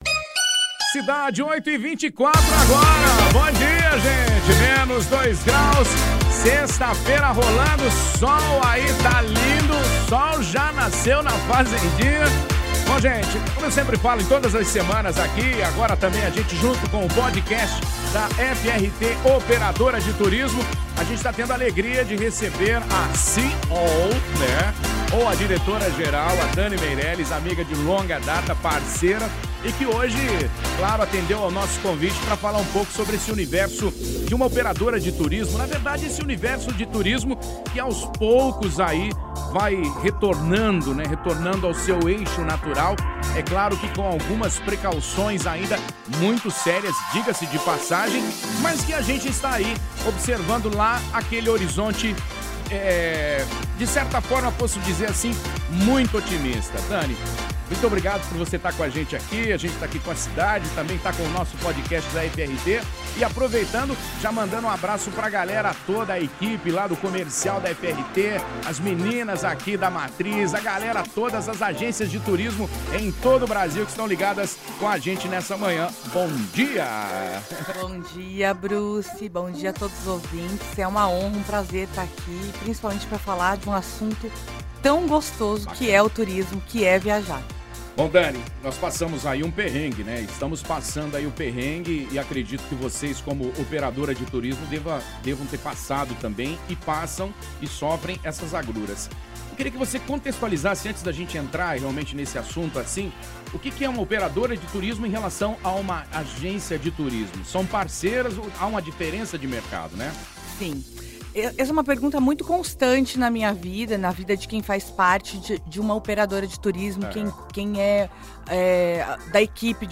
EntrevistaRadioCidadeFM.mp3